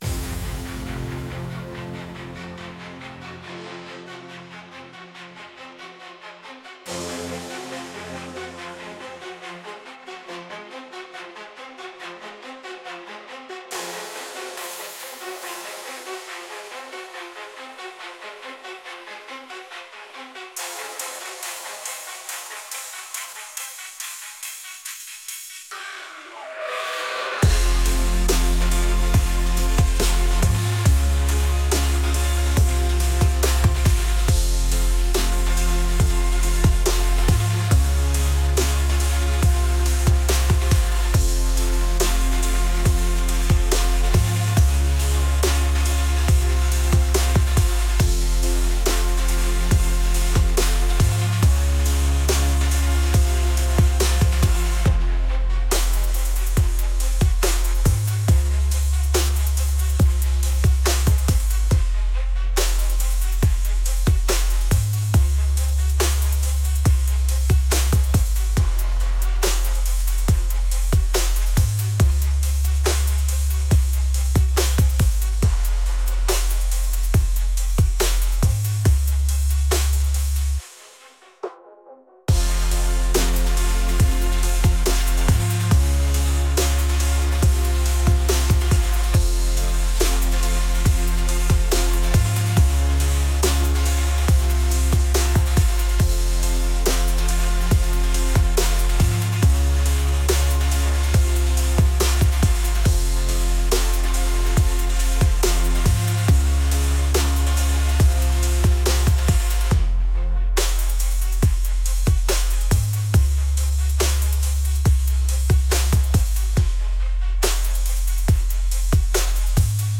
intense | heavy